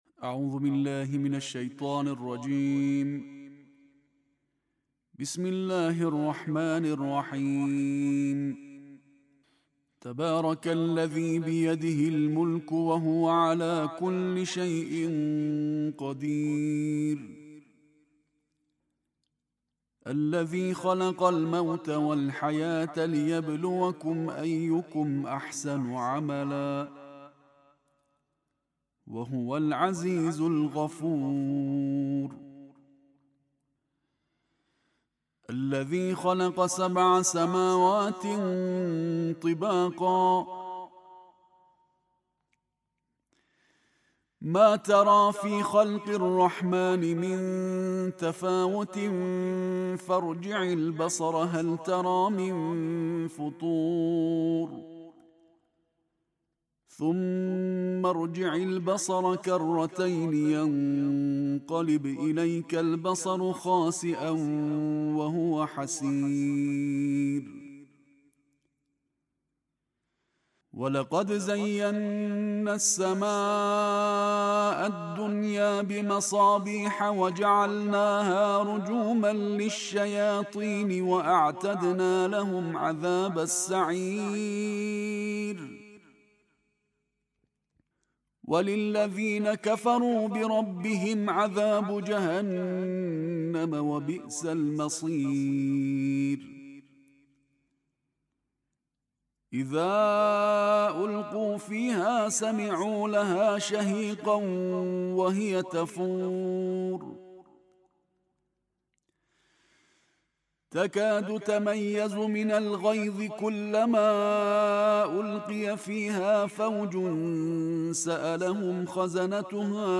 Araw-araw kasama ang Quran: Pagbigkas ng Tarteel ng Juz 29